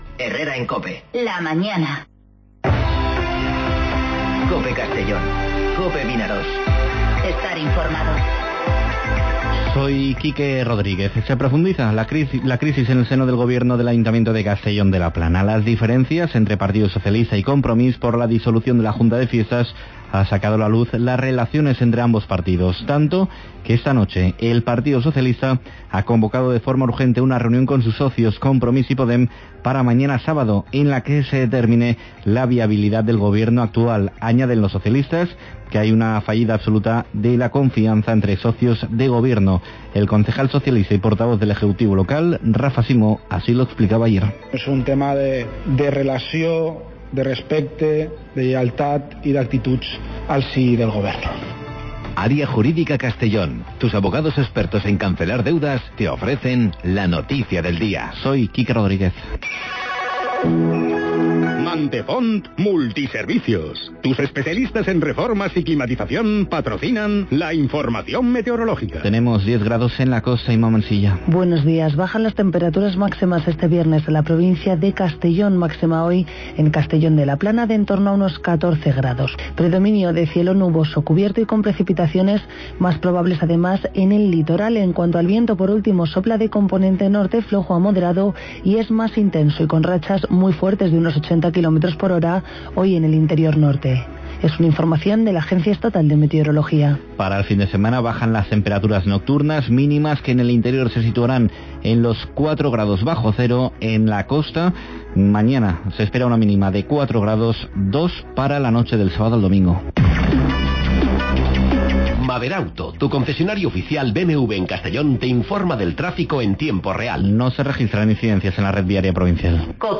Informativo Herrera en COPE Castellón (10/01/2020)